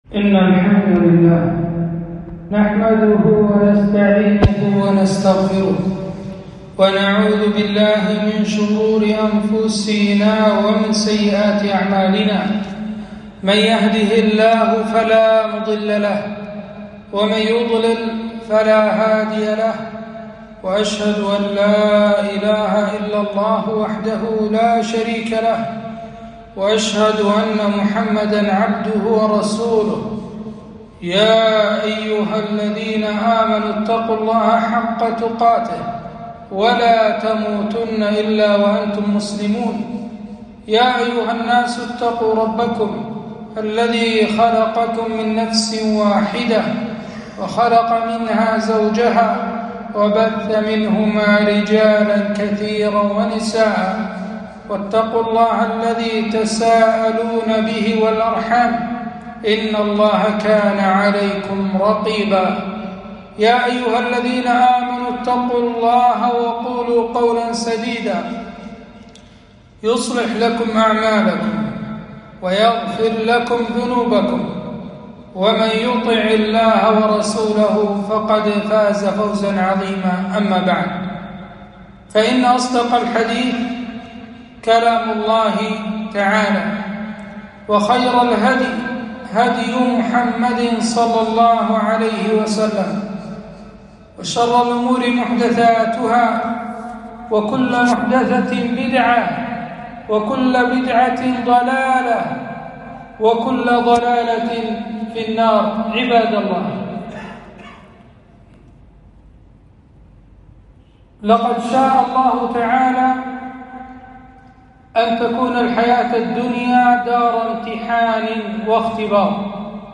خطبة - انتظار الفرج